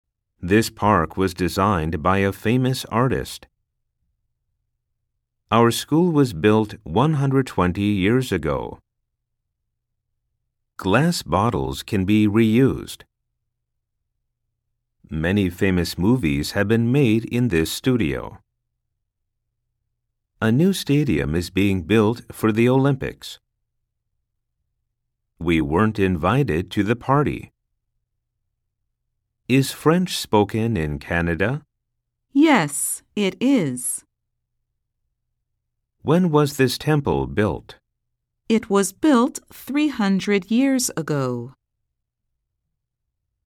Target例文 normal speed　サンプル音声（mp3）